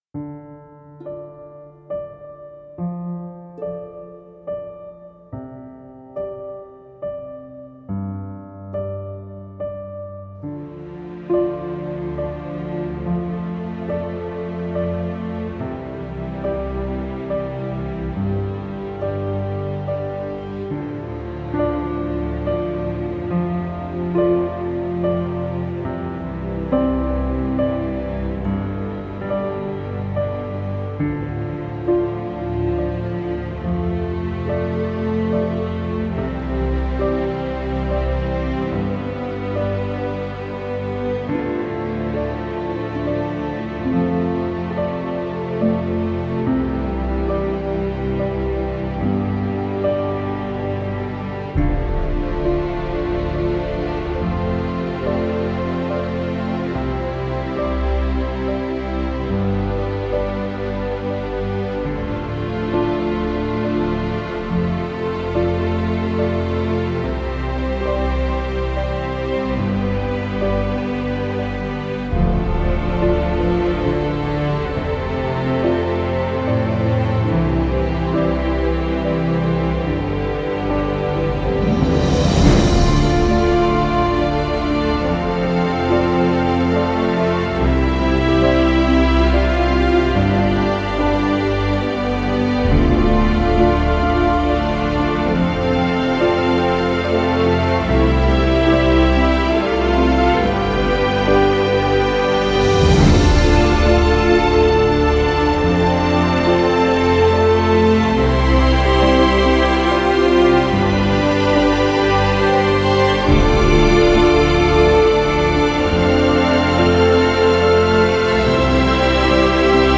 轻音乐
该BGM音质清晰、流畅